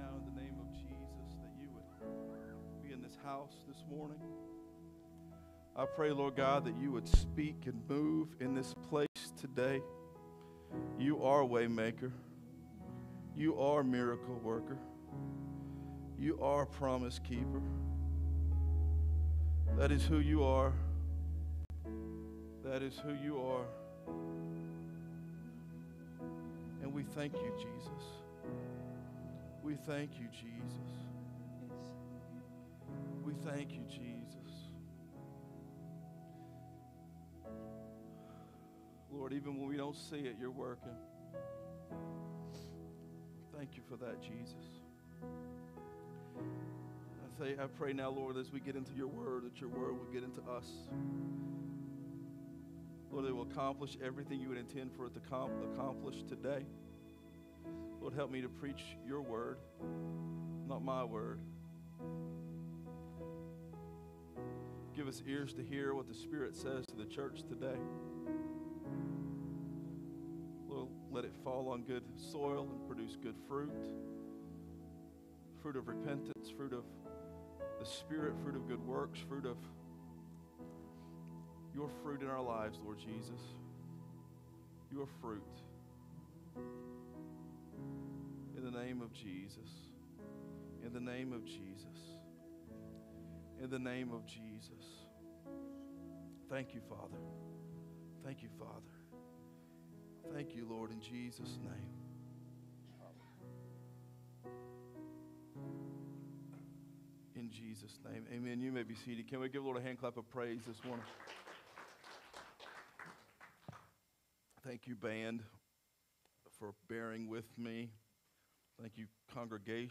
Sermons | Real Life Community Church